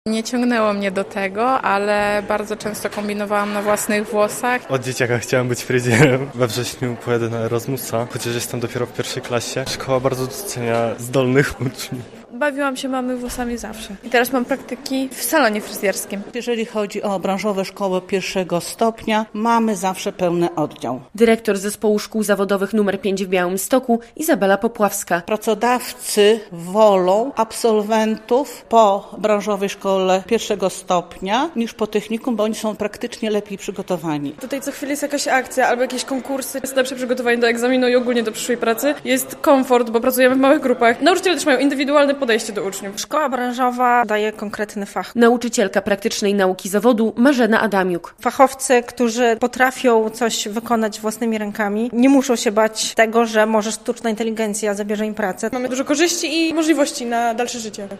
Konkurs fryzjerski w Zespole Szkół Zawodowych nr 5 w Białymstoku